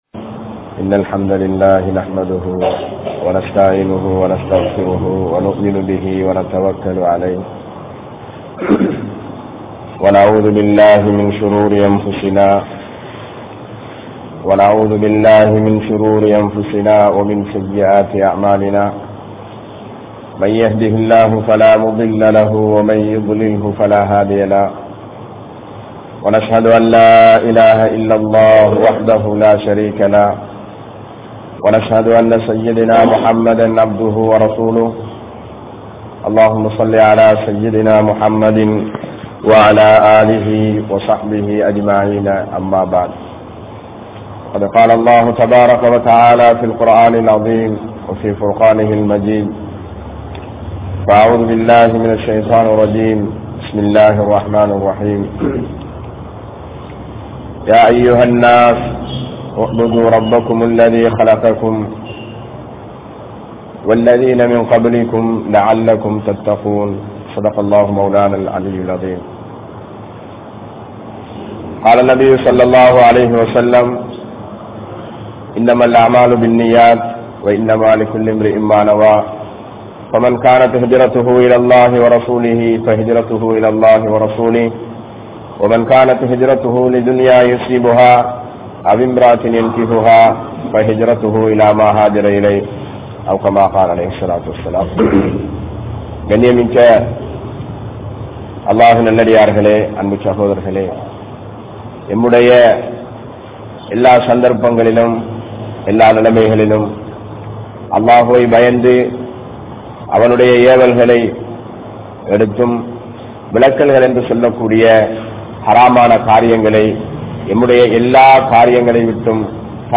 Islam Koorum Suththam (இஸ்லாம் கூறும் சுத்தம்) | Audio Bayans | All Ceylon Muslim Youth Community | Addalaichenai
Mathurankadawela Jumua Masjidh